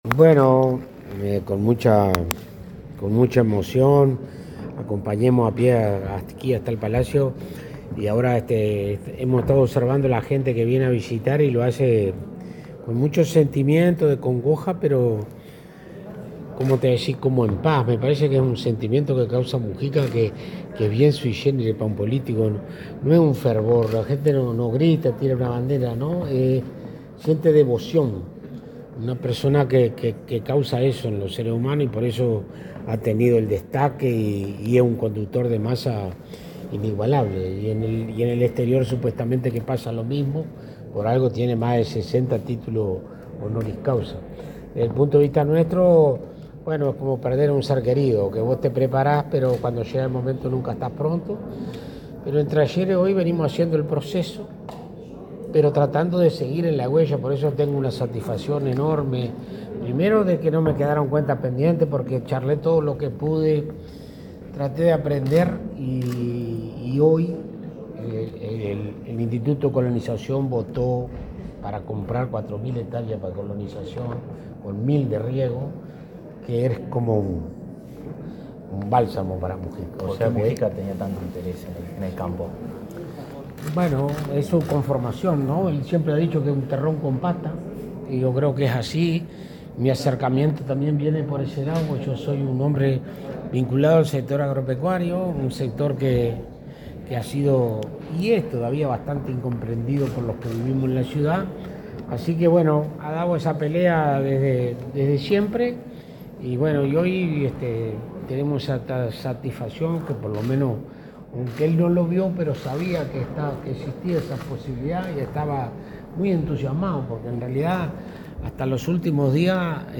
Declaraciones del ministro de Ganadería, Alfredo Fratti
El ministro de Ganadería, Agricultura y Pesca, Alfredo Fratti, dialogó con la prensa en el Palacio Legislativo, acerca de la figura del exmandatario